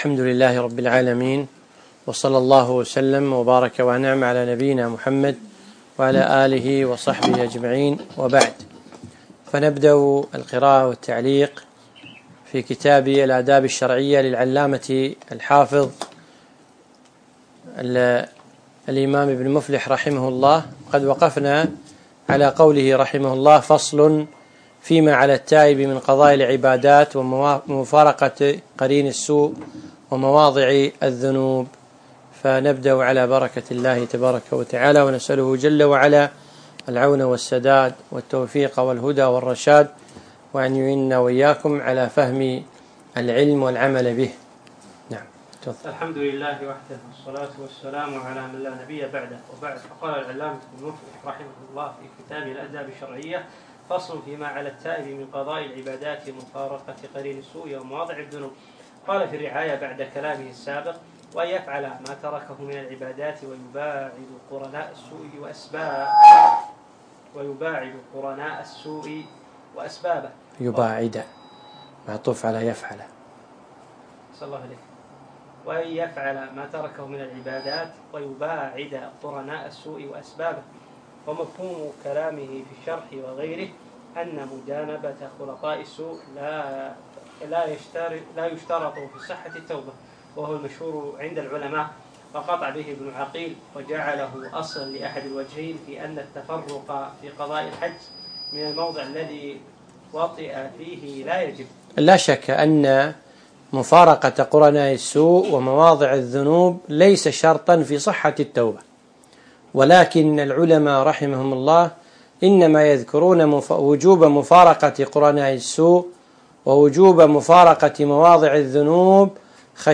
الدرس السابع